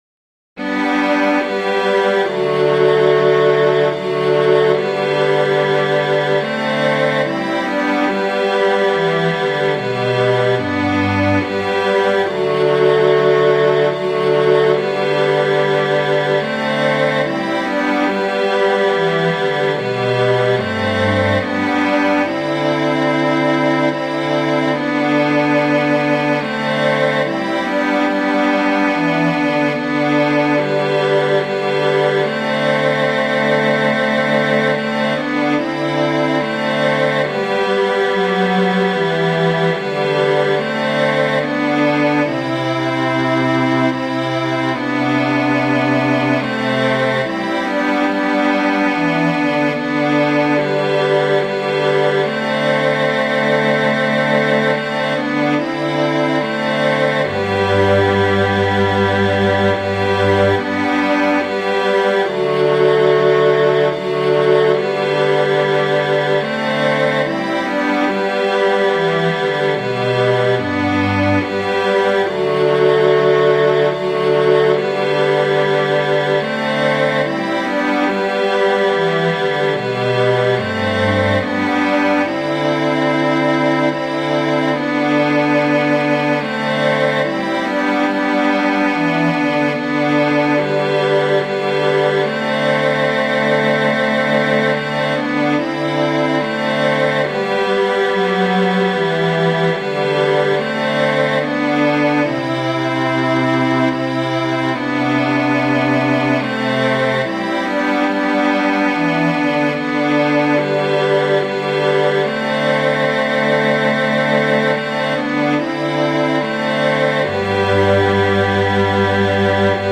Musiche digitali in mp3 tratte dagli spartiti dell'opuscolo